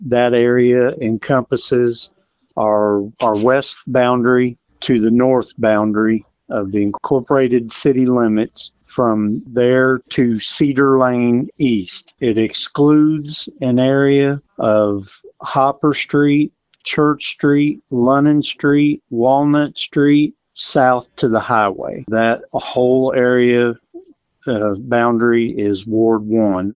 Mayor Braim shares the boundaries of the ward.